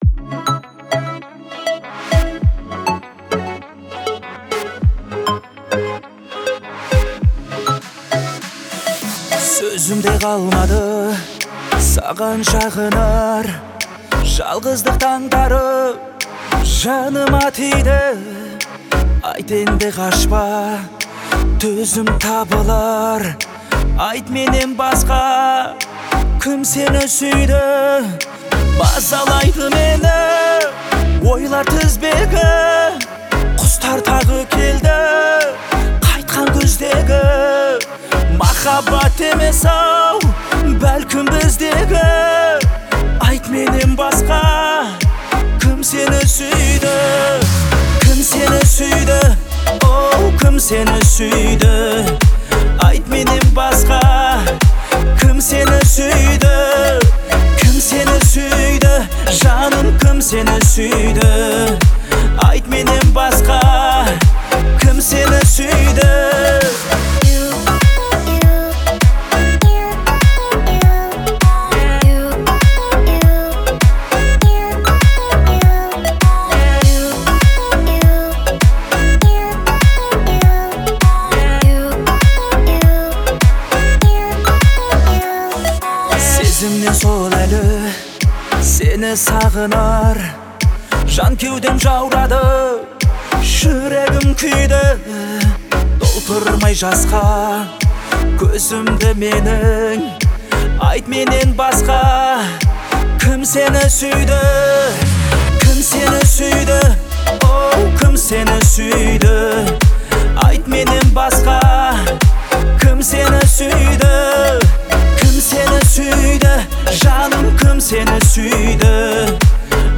это трогательный казахский романс